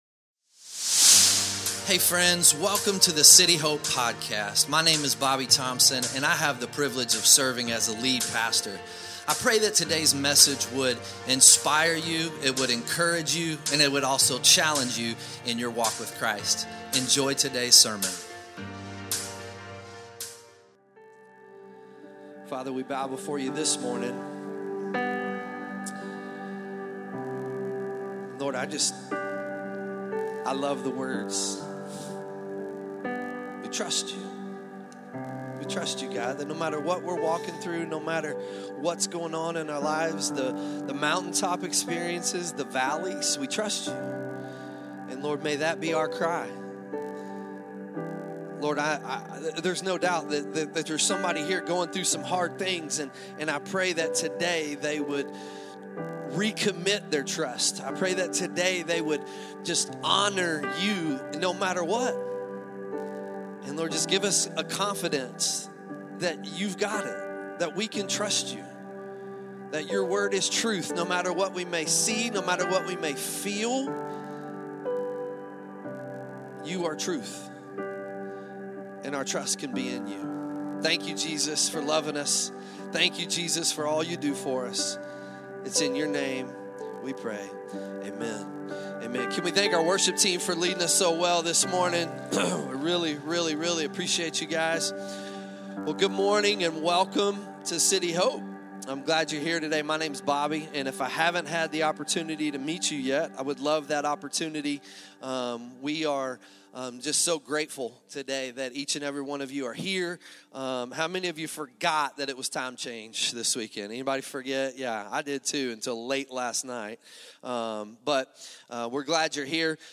2026 Sunday Morning For the singles….Culture will tell you to settle for convenience.